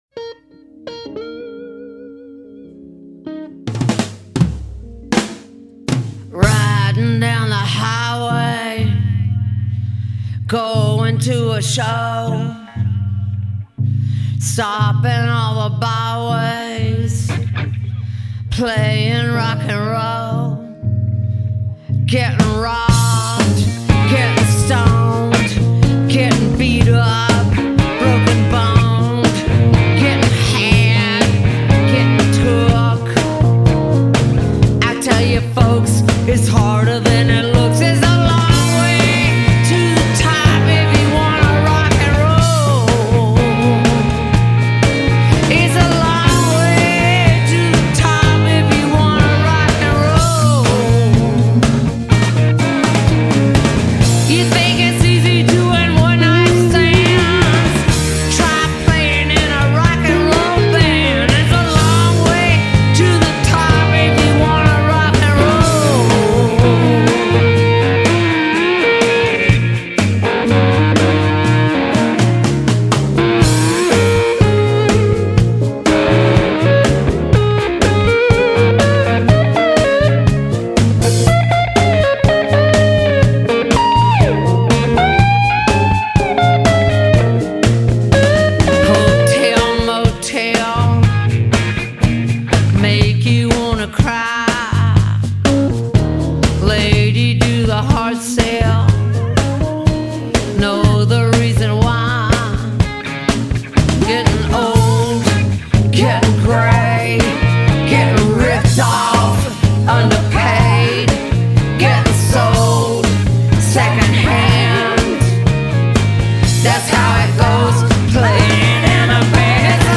rollicking version
she rocks out with the best of them.
really showcases the tight band